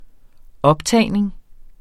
Udtale [ ˈʌbˌtæˀjneŋ ]